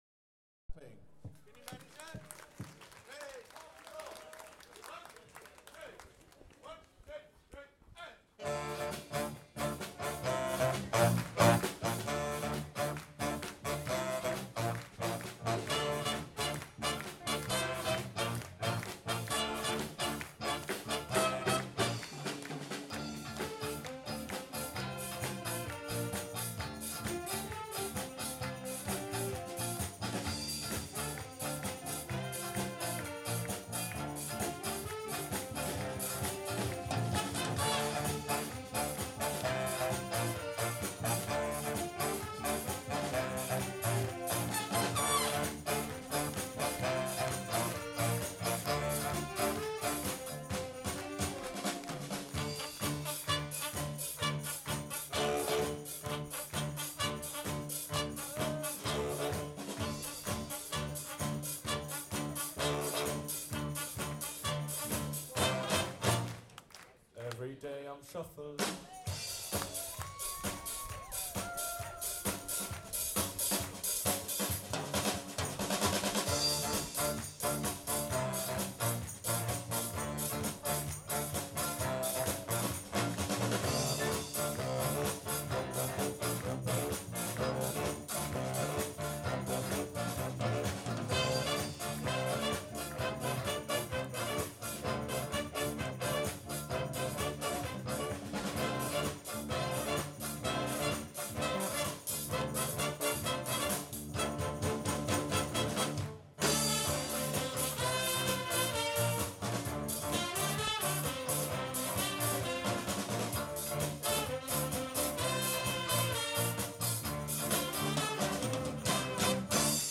Big Band in Charlerio